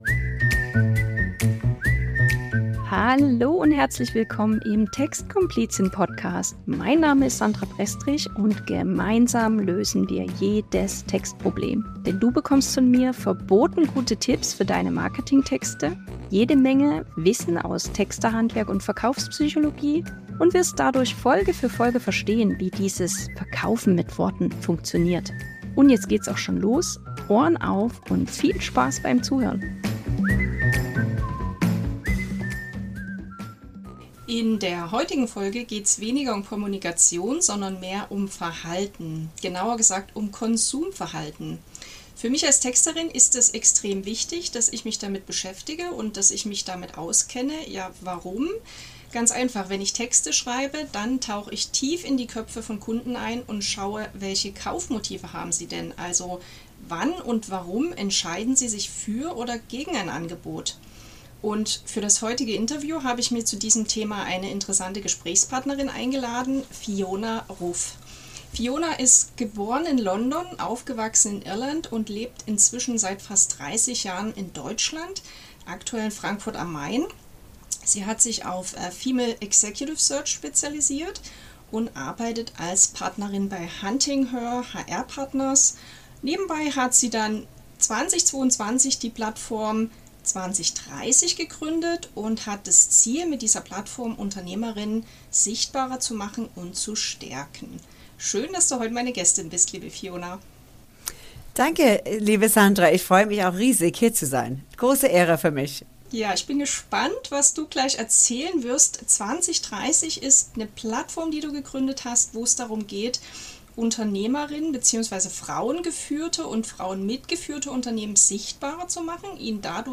Wir sprechen im Interview darüber, warum es wichtig ist, Frauen zu stärken und wie jede(r) einen Beitrag zu mehr Frauen in der Wirtschaft leisten kann.